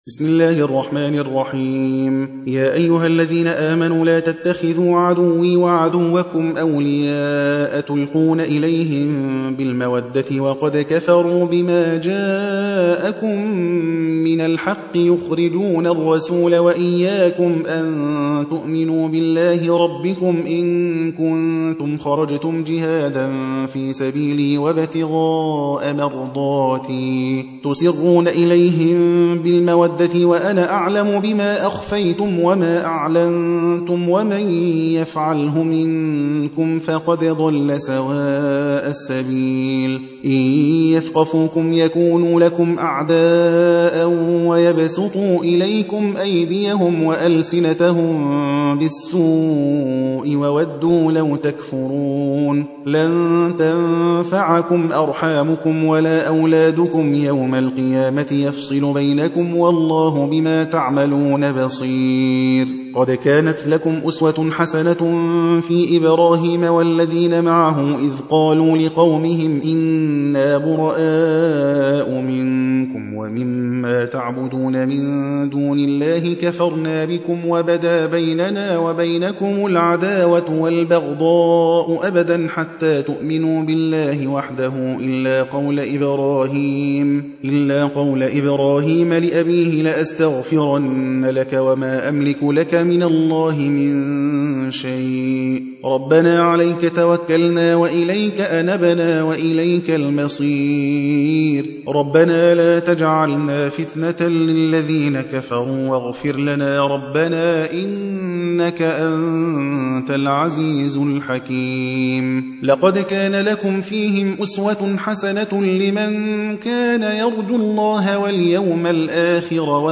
60. سورة الممتحنة / القارئ